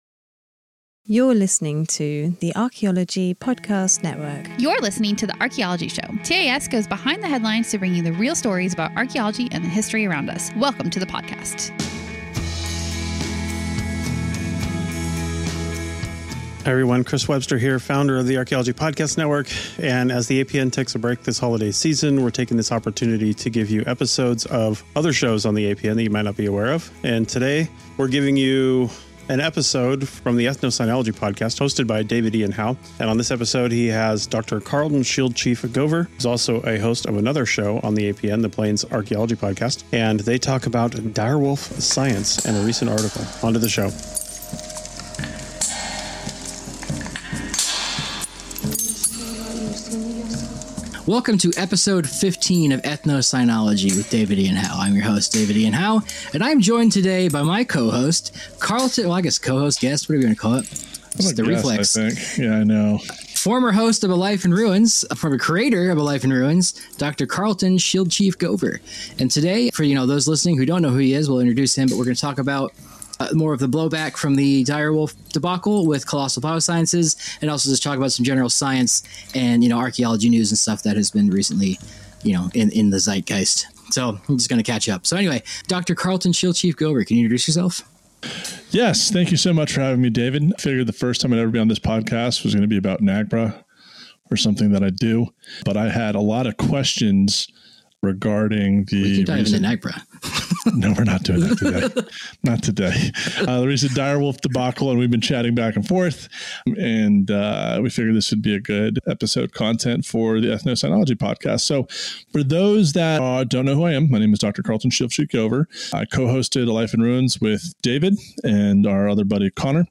1 Live Readings! 1:01:50